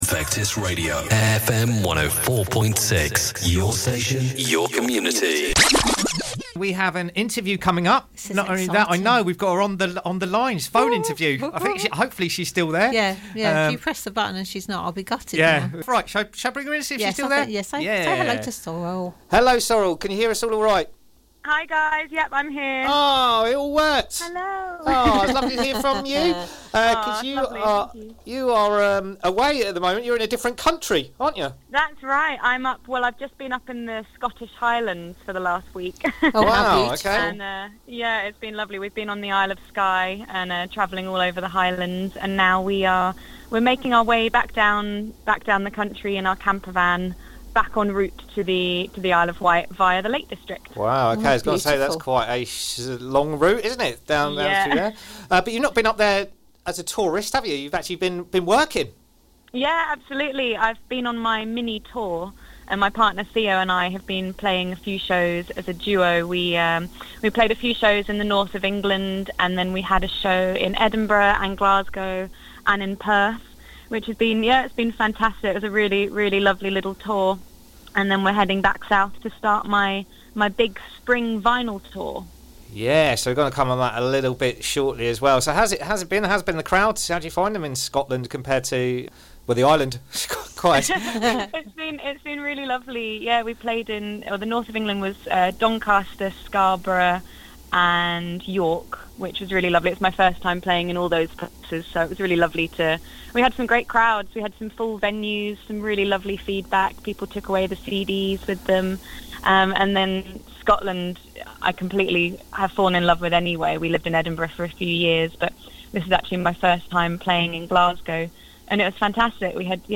live from the Highlands in Scotland